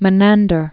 (mə-năndər) 342?-292?